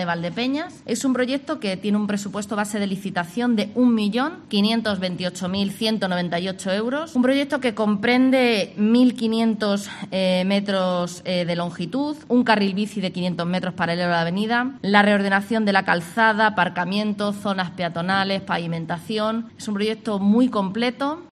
Sara Martínez, portavoz del equipo de gobierno